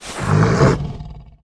Index of /App/sound/monster/ice_snow_dog
fall_1.wav